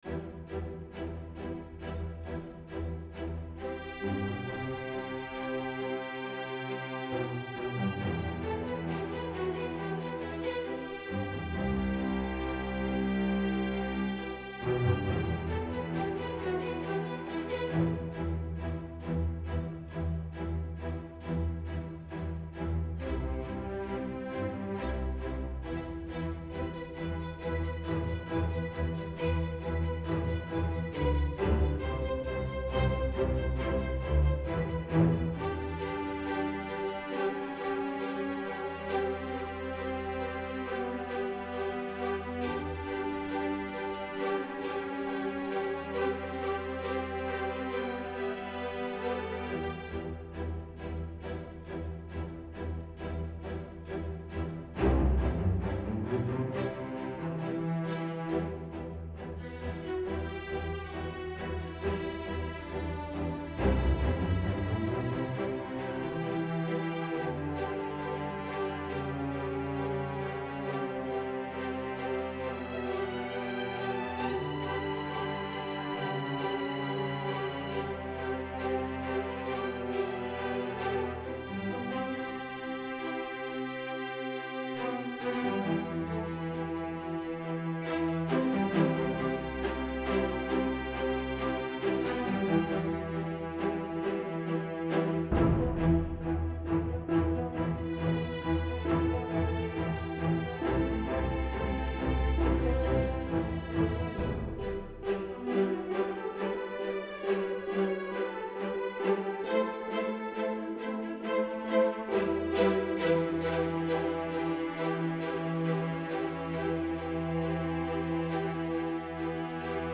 This forced me into working with just this sound set.
arrange it to make it more orchestra and a bit longer